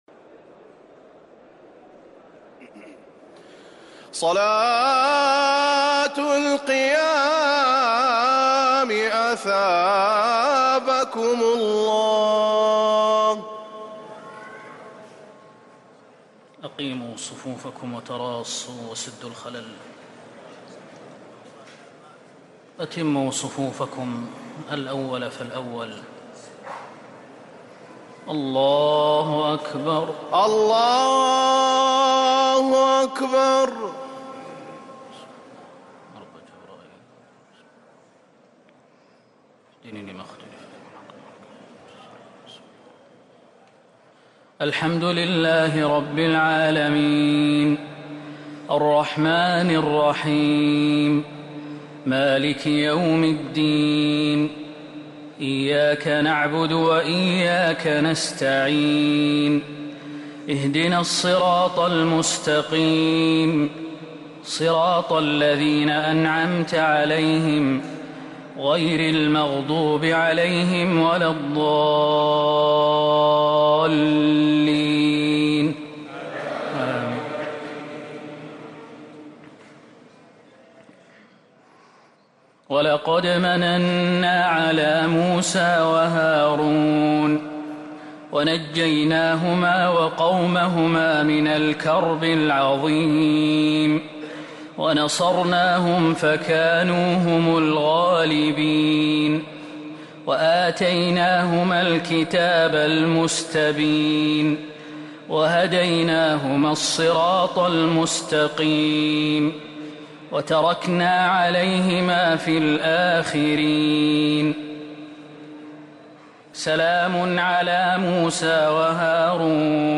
تهجد ليلة 25 رمضان 1444هـ سورة الصافات (114-182)+ ص كاملة + الزمر(1-40) |Tahajjud 25st night Ramadan 1444H -Surah As-Saaffat+ Sad + Az-Zumar > تراويح الحرم النبوي عام 1444 🕌 > التراويح - تلاوات الحرمين